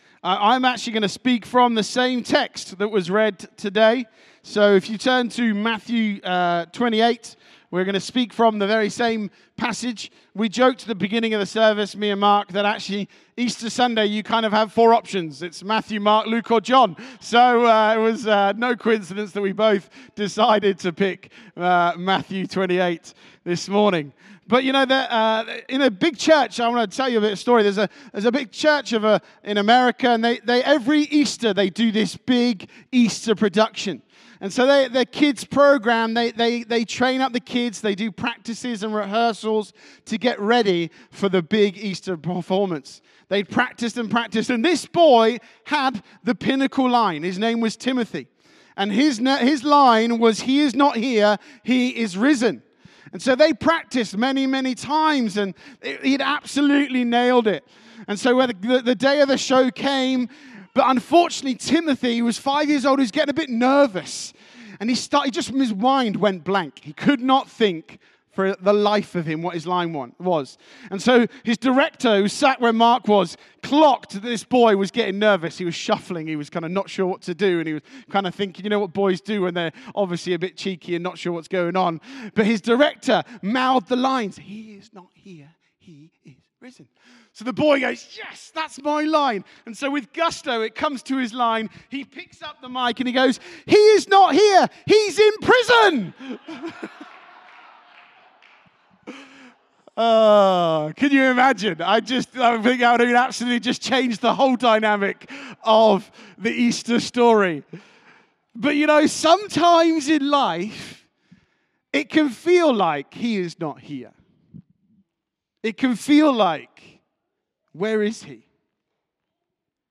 Sermon - Easter Sunday 2025